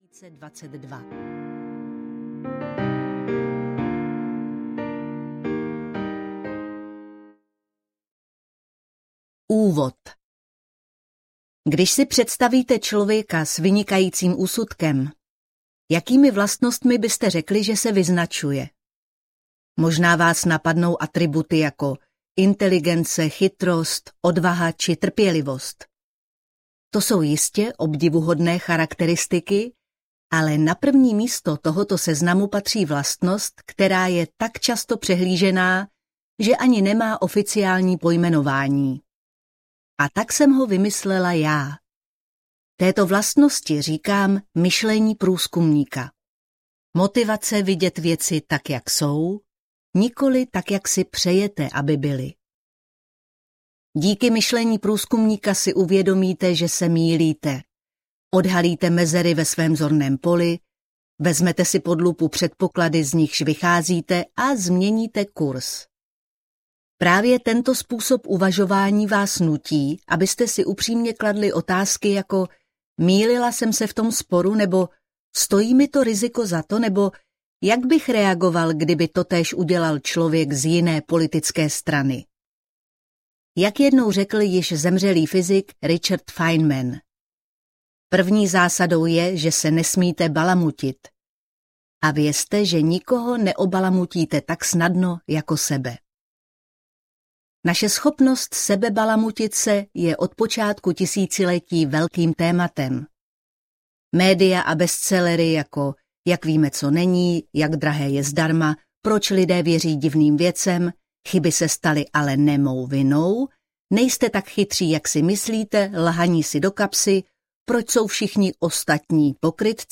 Konec sebeklamu audiokniha
Ukázka z knihy